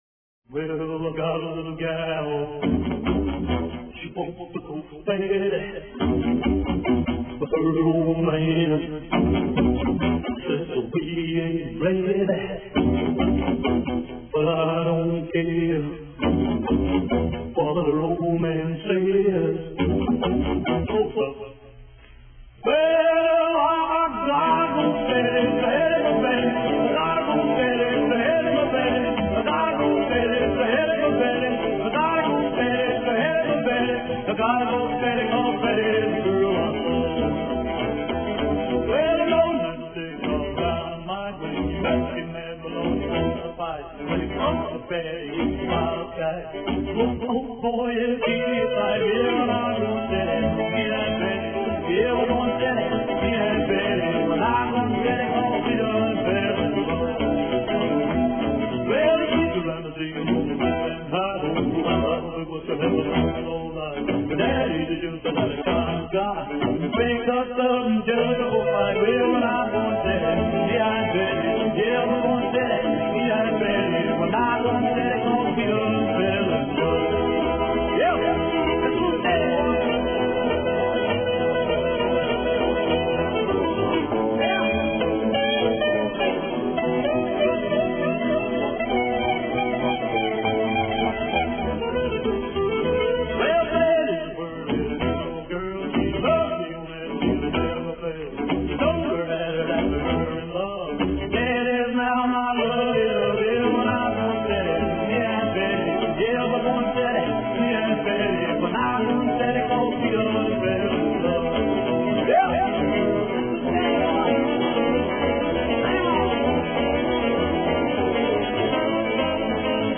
rockabilly
dark and moody textures